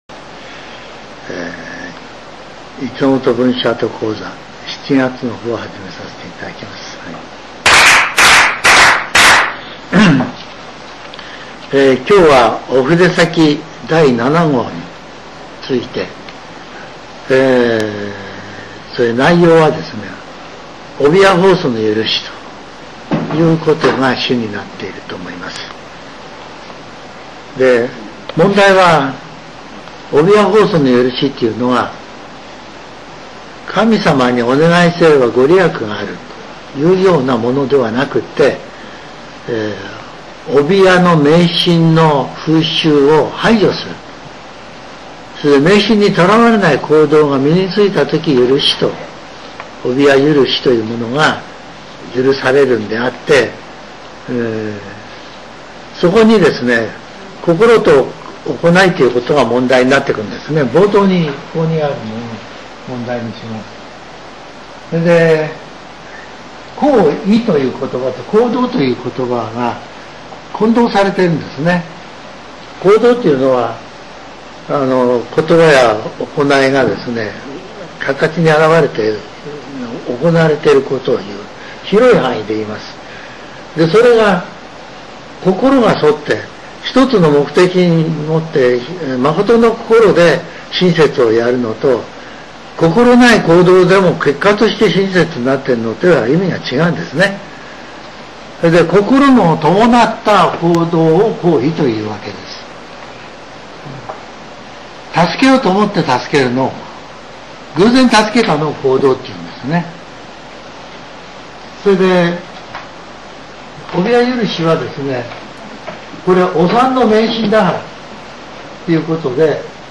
全70曲中62曲目 ジャンル: Speech